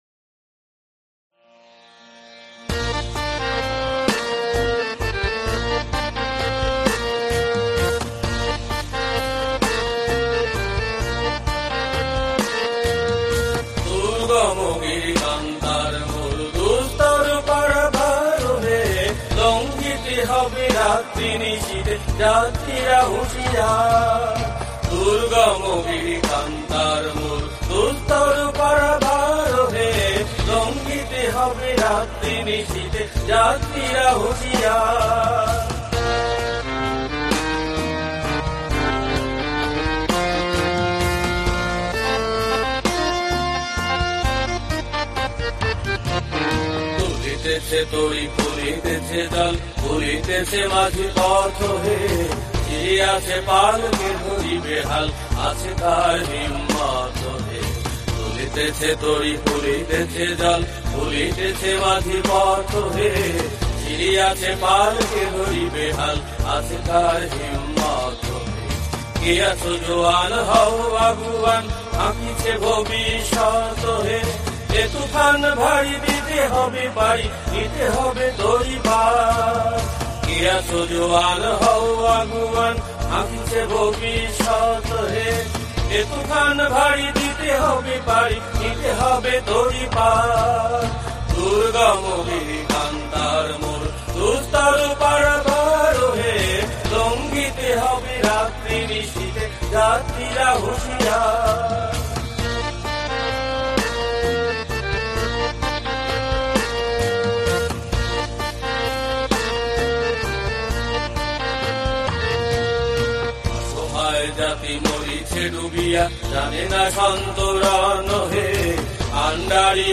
রাগ: বৃহন্নট-কেদারা, তাল: একতাল
• পর্যায়: উদ্দীপনামূলক
• সুরাঙ্গ: রাগাশ্রয়ী